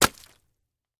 Звуки арбуза
упал кусочек на пол